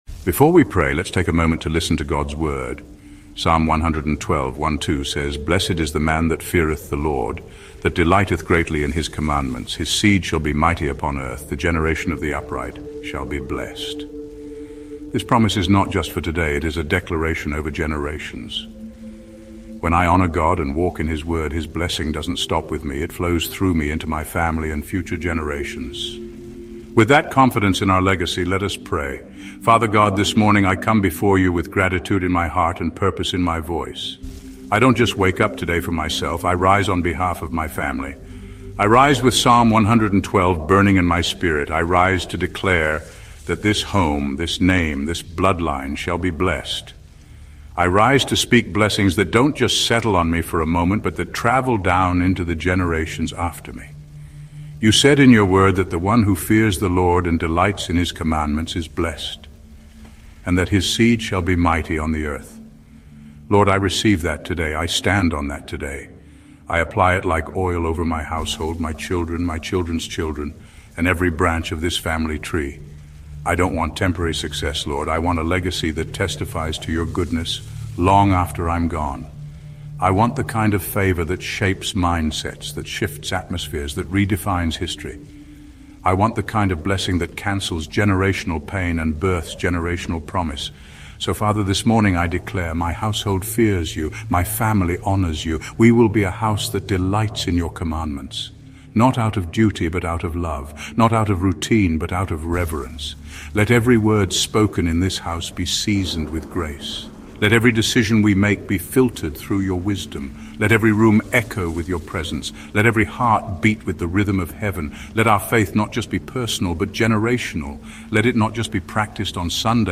Before every episode of The Bible Stories: Words of Life, we want to share something many listeners quietly appreciate the moment they press play after a long day. All advertisements are placed at the very beginning of the episode so nothing interrupts the experience once the story begins.